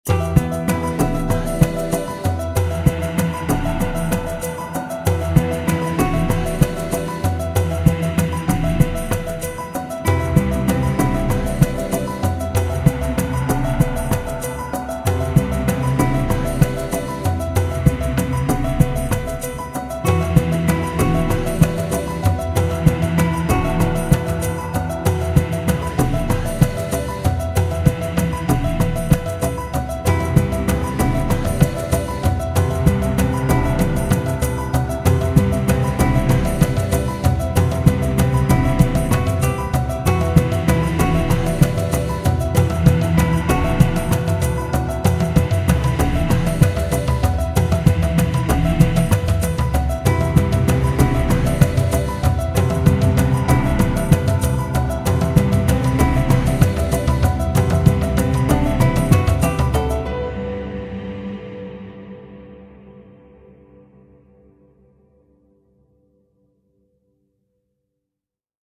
Soundtrack with an African Feel!
Tribal drums, moving panoramic
themes and atmospheres with beautiful African voices